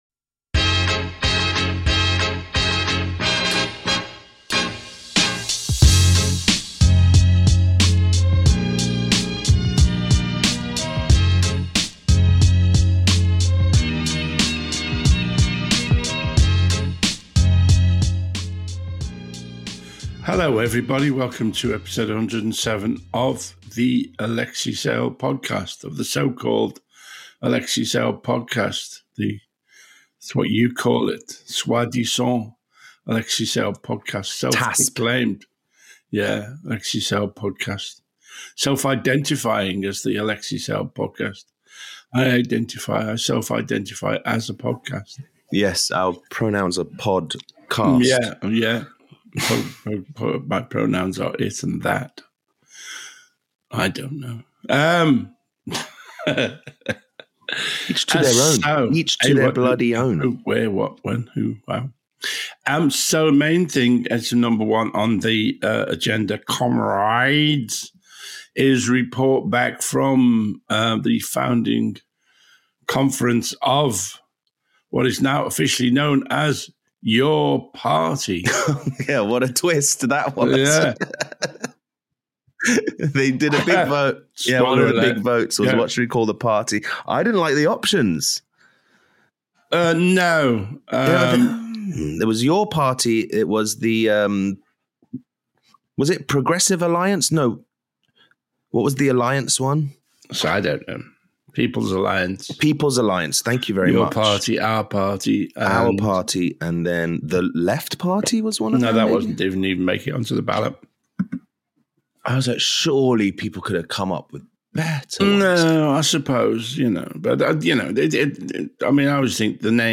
If you listen to The Alexei Sayle podcast you will not be disappointed, unless you are expecting something other than an old man talking crap.
Comedian Chat